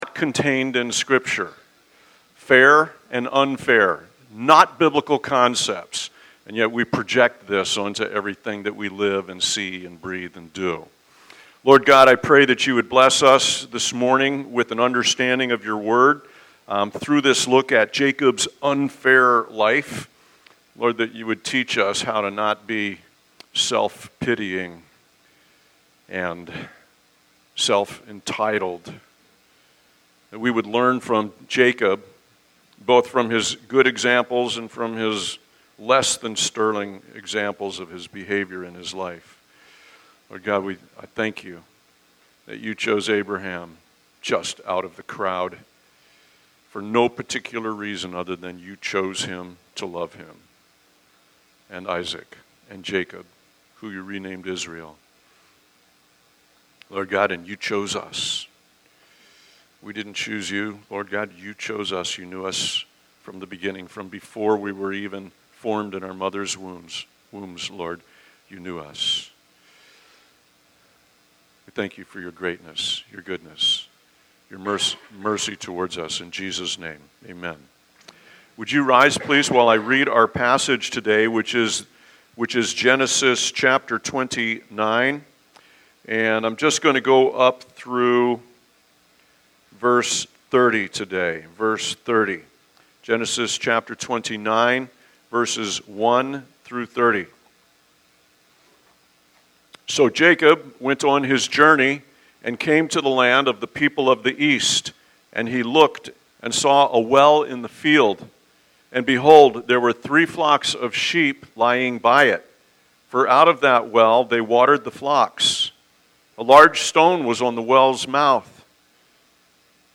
by Calvary Chapel Leesburg | Jun 29, 2025 | Sermons